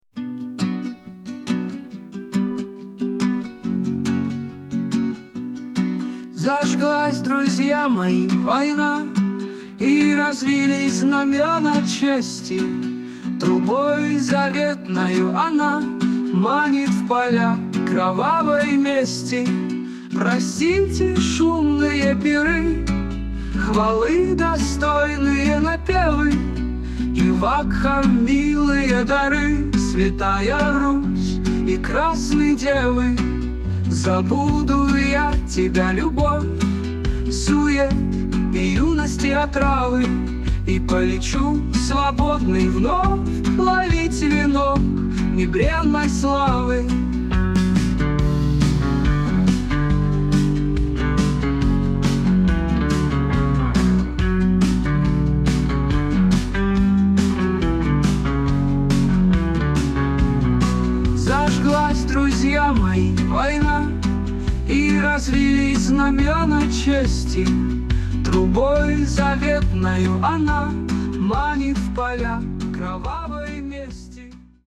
• Качество: Хорошее
• Категория: Детские песни
аудиостих, гитара